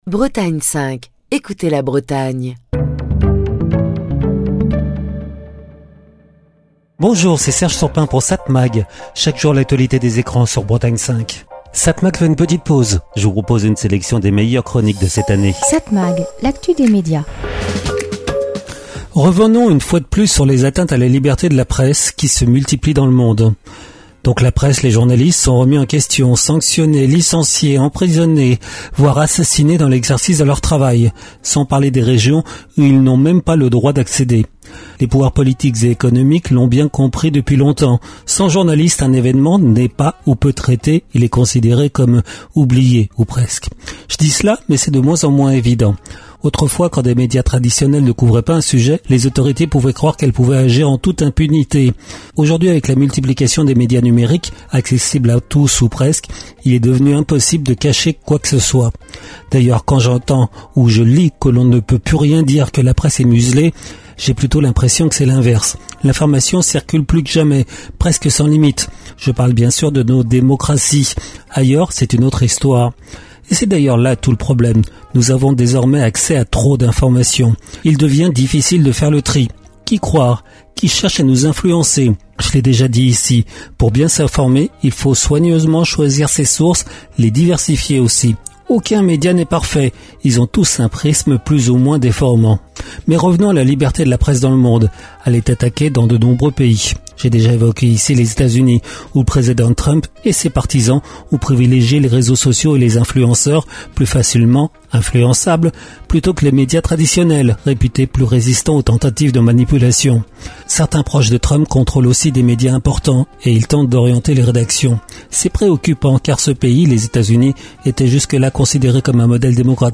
Chronique du 31 juillet 2025.